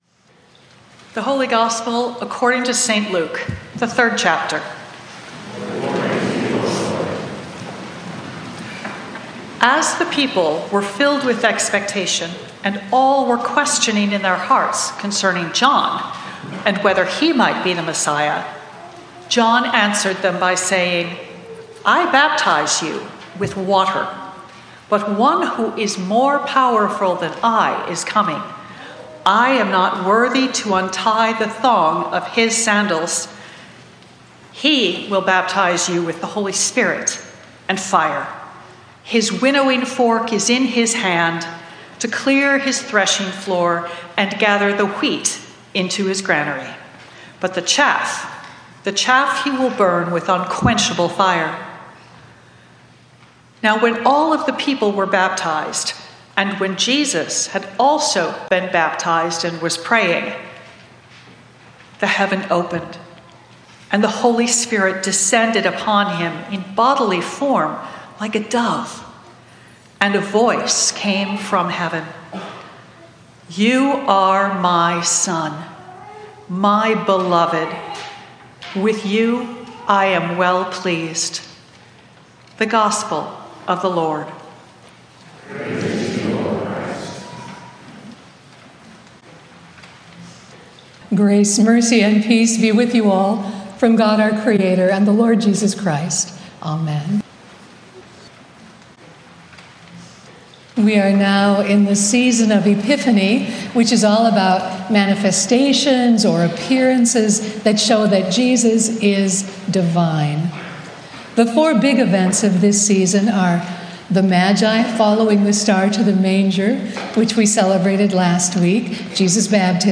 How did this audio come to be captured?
Includes sermons from our Sunday morning 9:45 worship services.